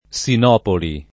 Sinopoli [ S in 0 poli ]